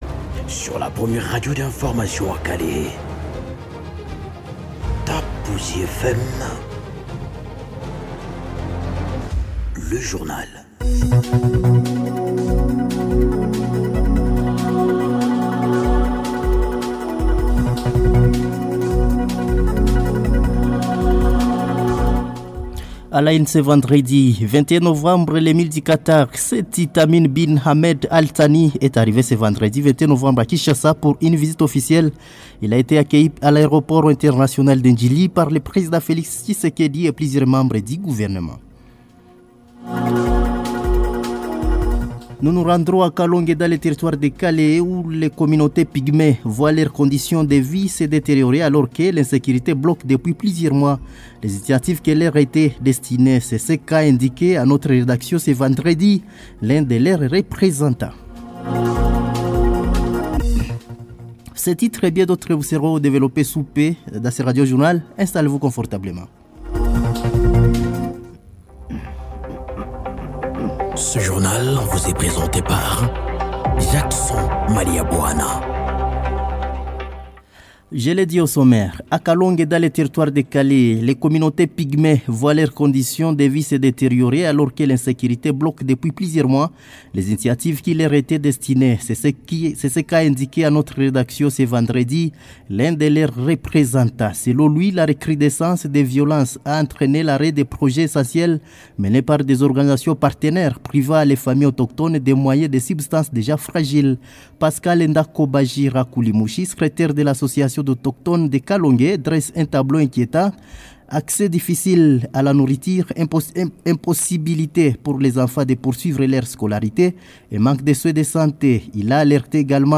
Journal parlé 21 novembre soir 2025